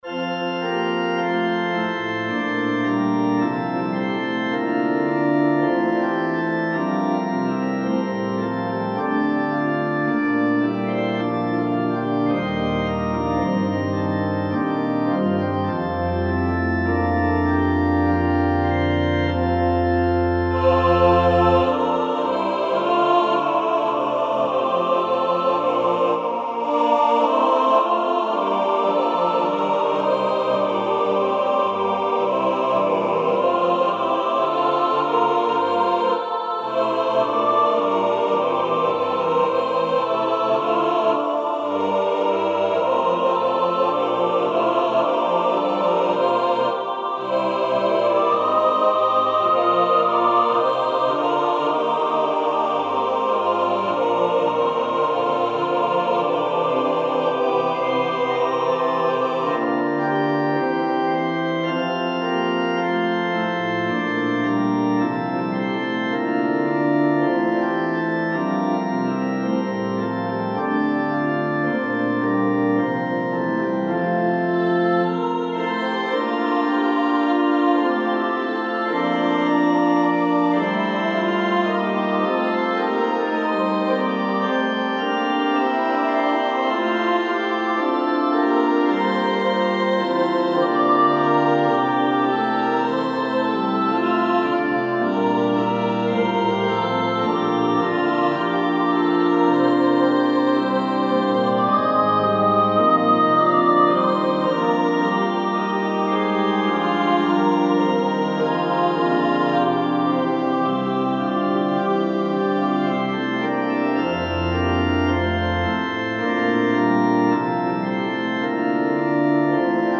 • Music Type: Choral
• Voicing: SATB
• Accompaniment: Organ
set in rich harmony and texture